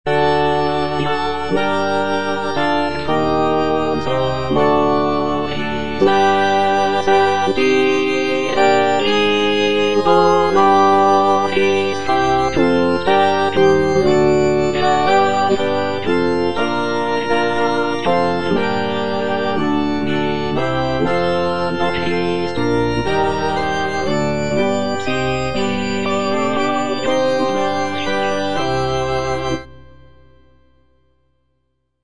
G.P. DA PALESTRINA - STABAT MATER Eja Mater, fons amoris (alto I) (Emphasised voice and other voices) Ads stop: auto-stop
sacred choral work